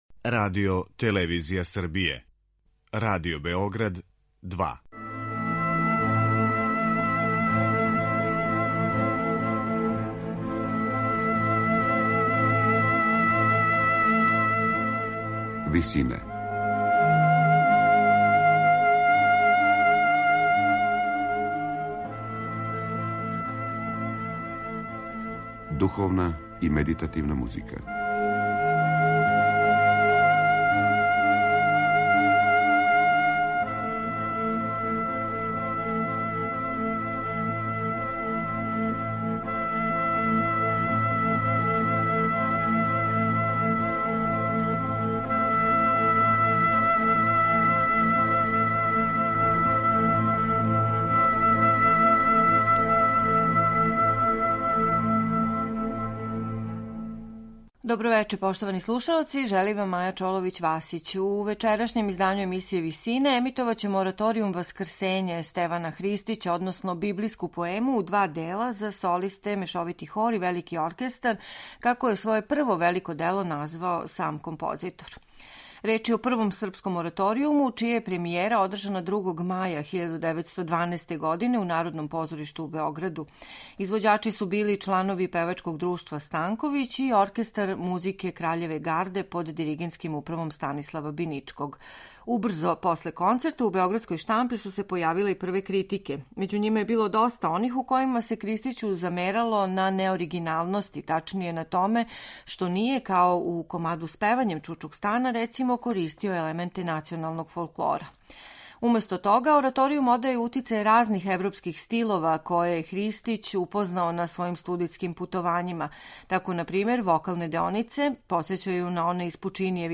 Емисија је посвећена композицији „Васкрсење” ‒ библијској поеми у два дела за солисте, мешовити хор и велики оркестар, како је своје прво велико дело и први ораторијум у српској музици из 1912. године назвао Стеван Христић.